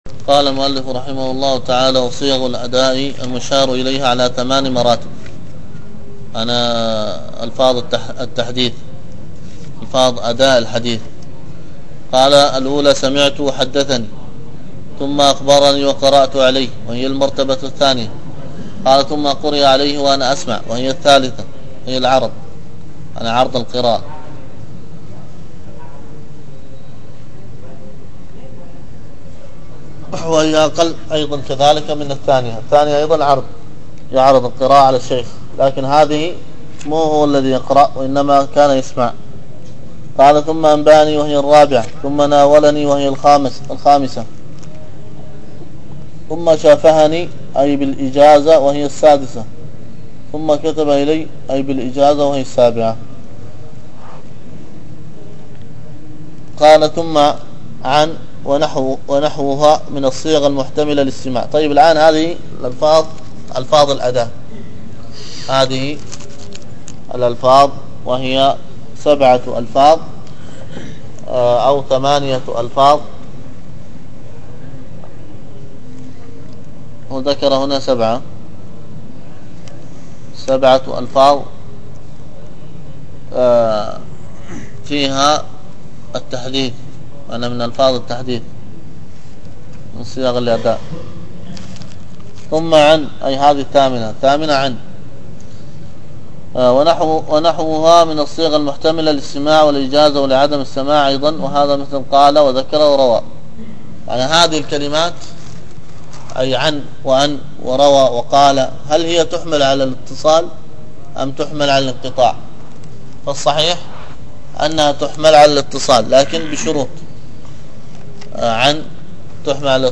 الدرس في الصحيح المسند مما ليس في الصحيحين 277، ألقاها